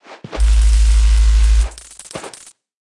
Media:Sfx_Anim_Ultra_Jessie.wav 动作音效 anim 在广场点击初级、经典、高手和顶尖形态或者查看其技能时触发动作的音效